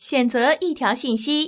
ivr-take_a_message.wav